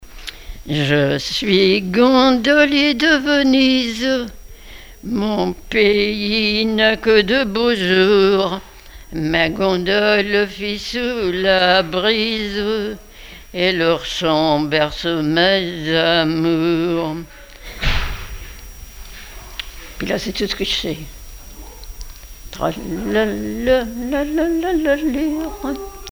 Genre strophique
Enquête sur les chansons populaires
Pièce musicale inédite